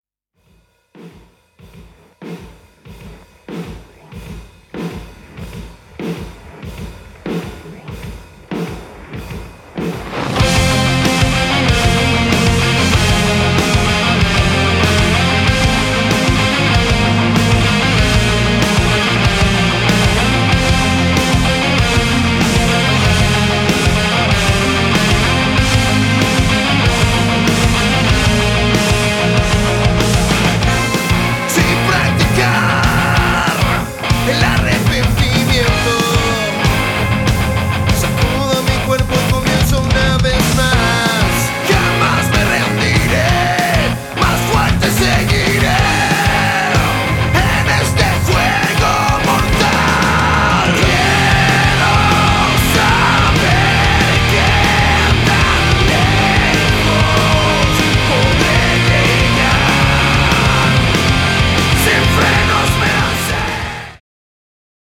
Metal Rock
Guitars
Bass & Vocals
Drums